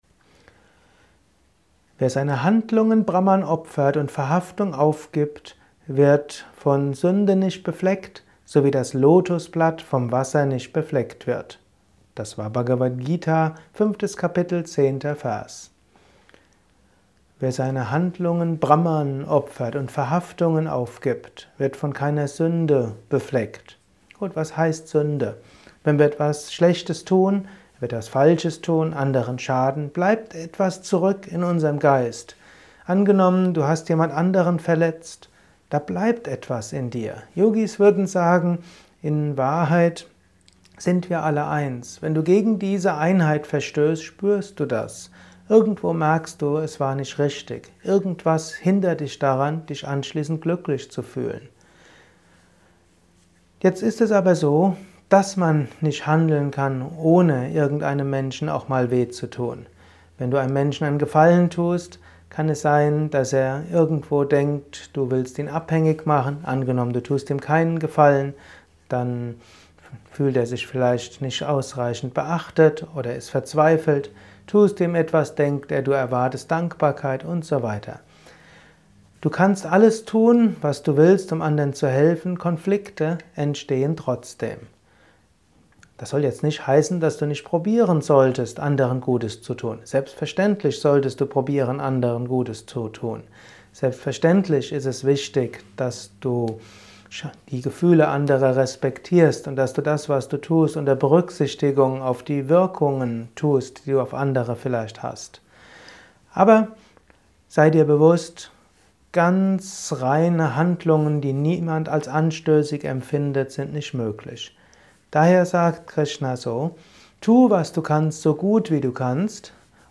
Kurzvorträge
Dies ist ein kurzer Kommentar als Inspiration für den